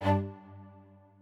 strings6_51.ogg